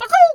pgs/Assets/Audio/Animal_Impersonations/chicken_2_bwak_08.wav
chicken_2_bwak_08.wav